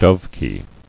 [dove·kie]